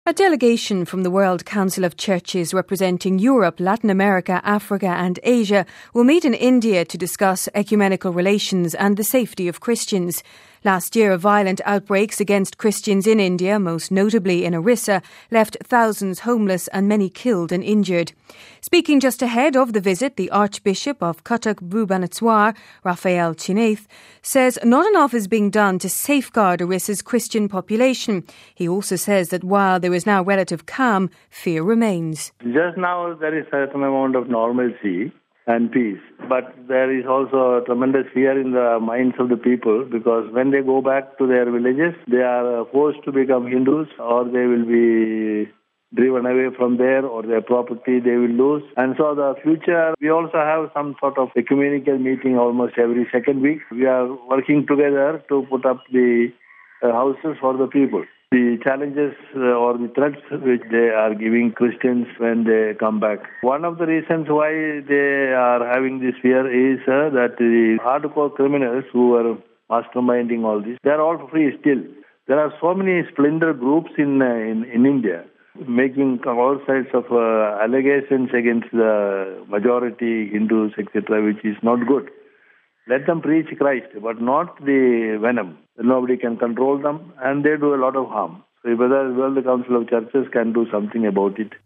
Speaking just ahead of the visit the Archbishop of Cuttack-Bhubaneswar, Raphael Cheenath says not enough is being done to safeguard Orissa’s Christian population. He also says that while there is now relative calm, fear remains.